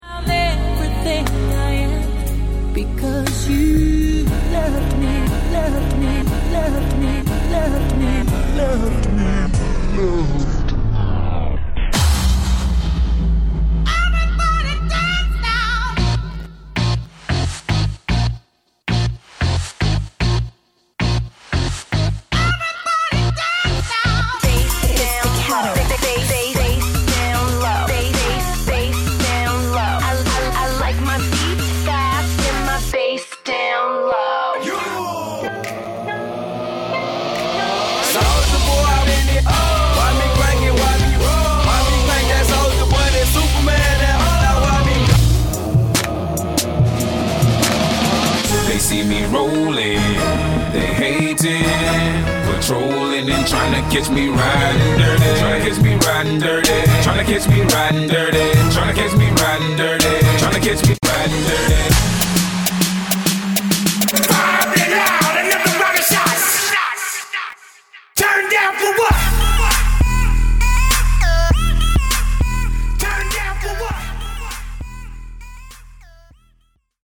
Wedding mashup creator
First dance mashup sample